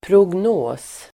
Uttal: [progn'å:s]